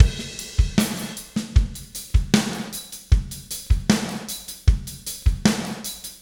Spaced Out Knoll Drums 04 Crash.wav